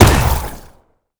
sci-fi_weapon_plasma_pistol_03.wav